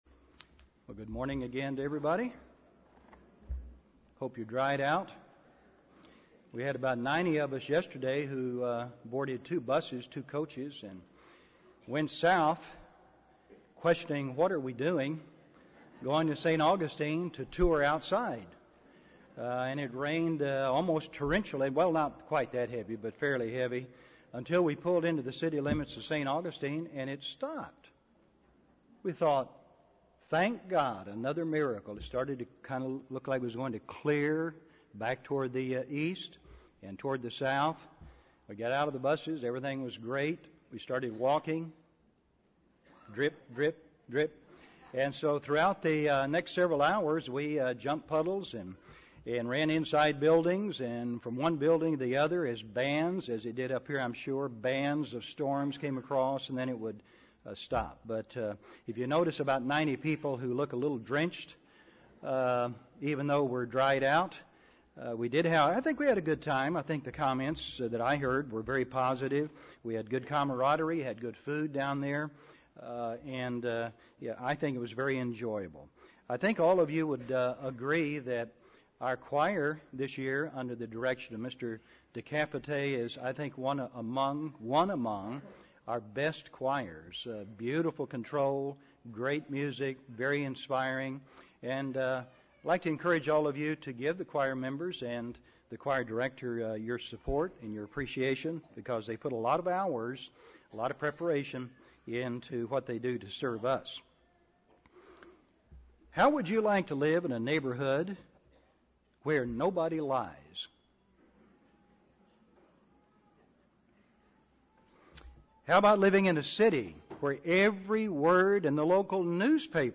This sermon was given at the Jekyll Island, Georgia 2007 Feast site.